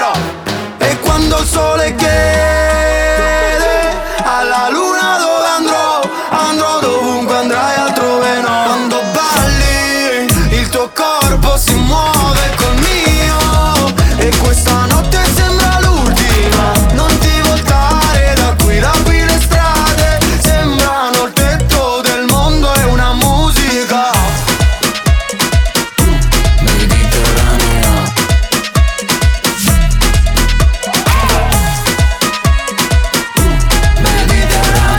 Жанр: Иностранный рэп и хип-хоп / Поп / Рэп и хип-хоп